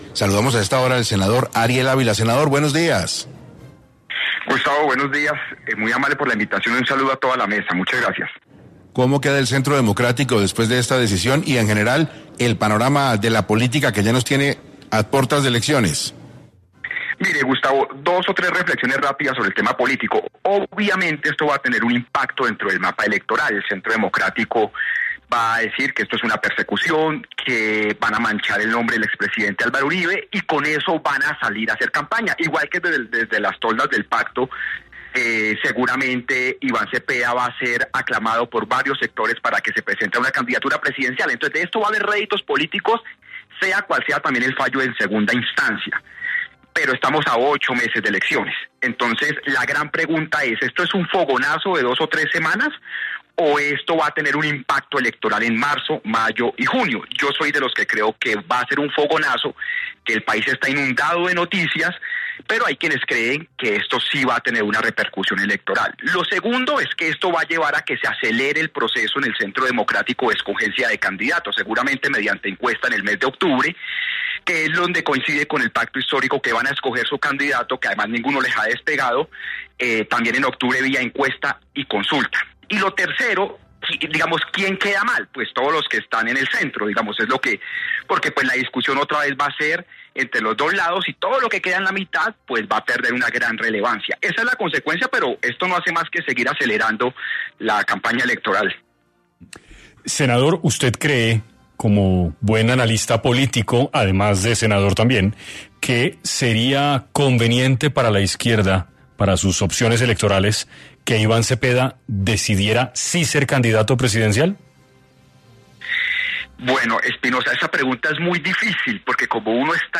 En Caracol Radio estuvo Ariel Ávila explicando las implicaciones tras el fallo del expresidente Uribe.